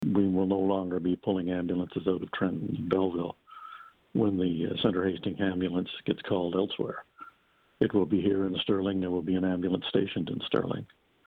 The warden of Hastings County Bob Mullin says response times will improve for residents in the central part of the county.